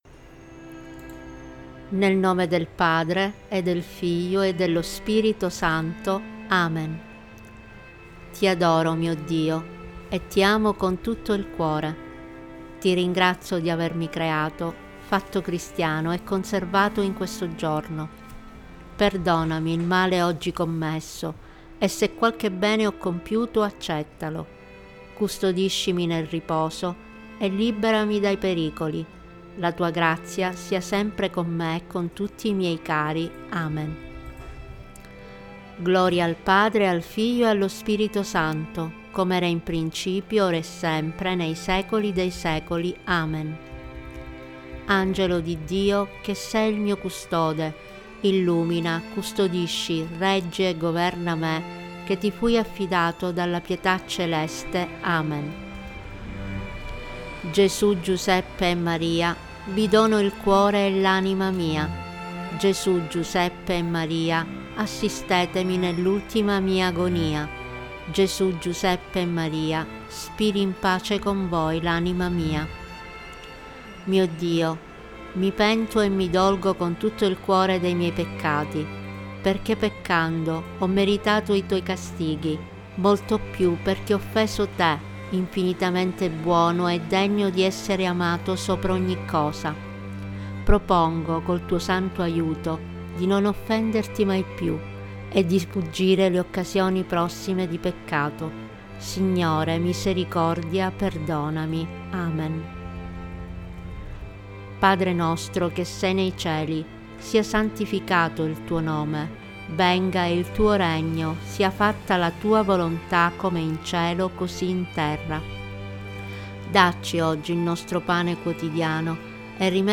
Preghiere-della-sera-Tempo-di-preghiera-musica.mp3